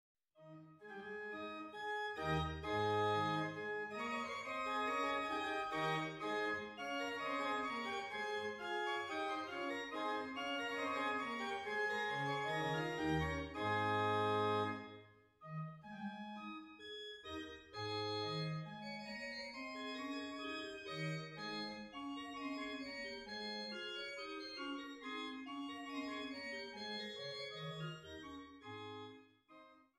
Orgel in Forchheim, Pfaffroda, Nassau und Ponitz